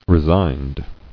[re·signed]